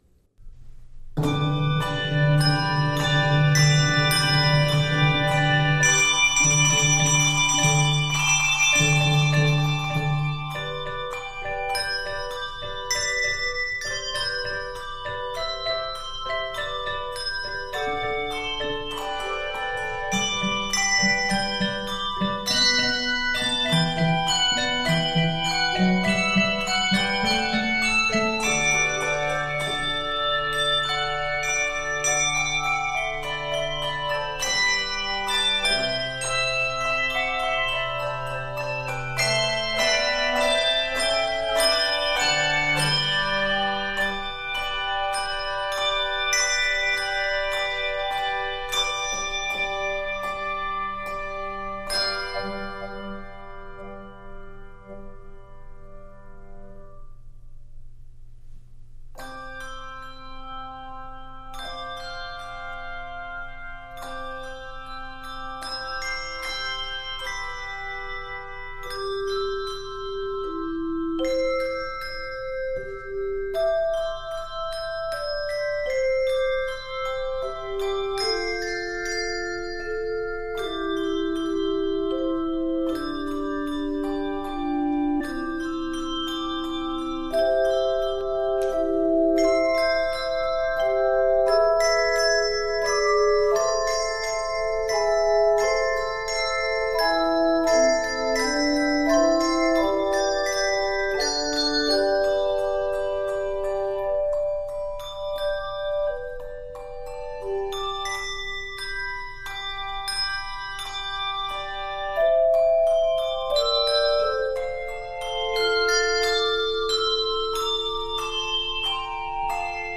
This joyful arrangement
Begins in G major, modulates to C Major, then to F Major.
Octaves: 3-5